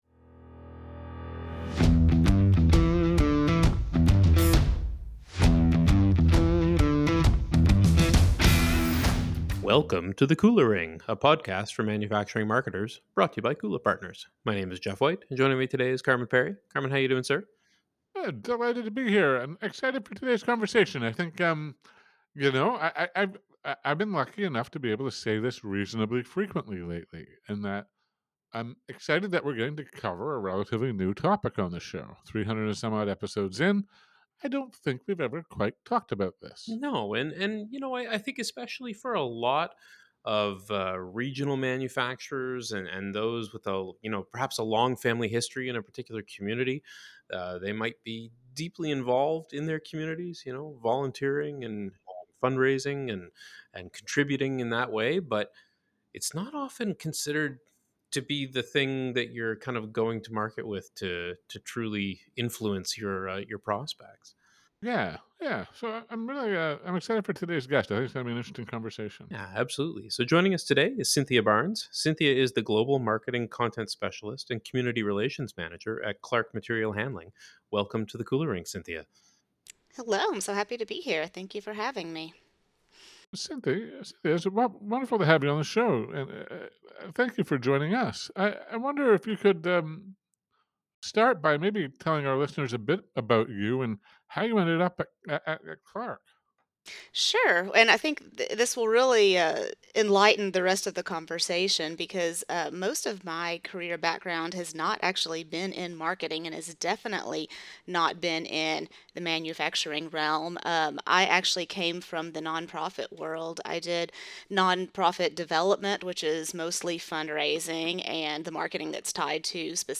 In this episode of The Kula Ring, we sit down with